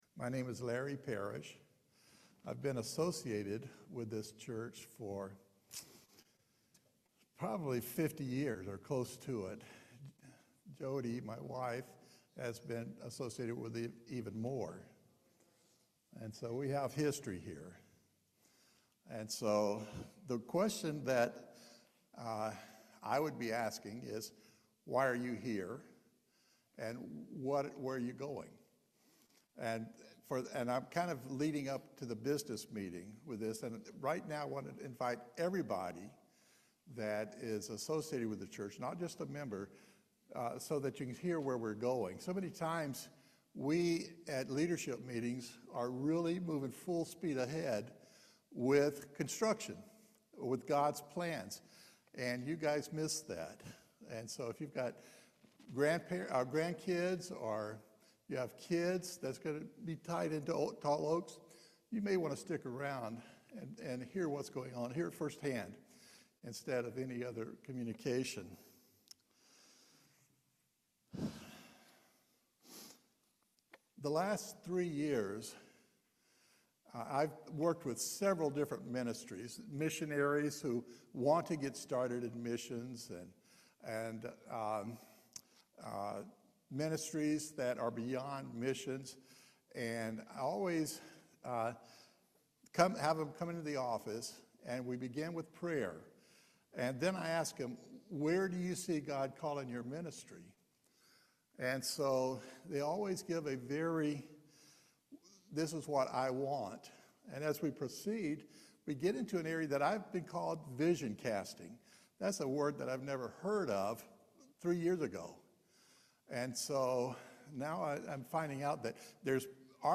Jeremiah 29:11 Service Type: Main Service Vision casting is getting beyond what we can do and seeing what He can do for us.